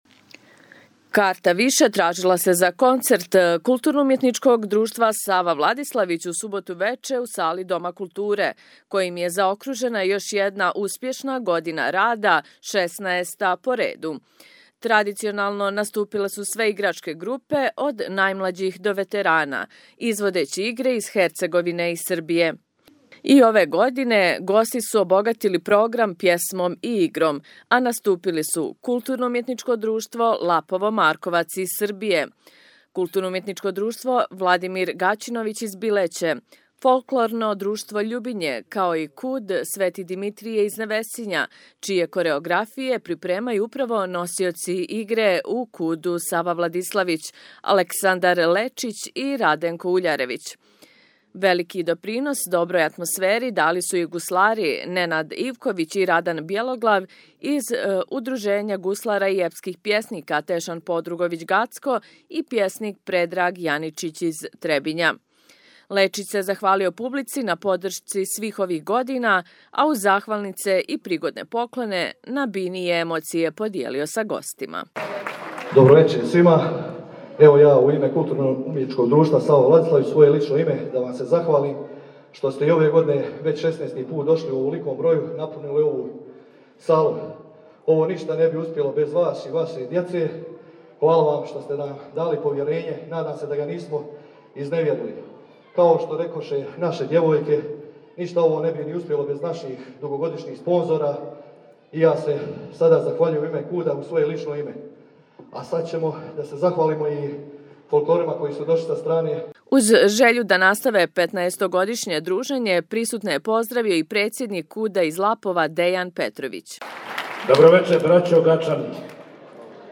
Карта више тражила се за концерт КУД „Сава Владиславић“ у суботу вече у сали Дома културе, којим је заокружена још једна успјешна година рада, шеснаеста по реду.
Традиционално наступиле су све играчке групе, од најмлађих до ветерана, изводећи игре из Херцеговине и Србије.
Koncert-KUD-SV.mp3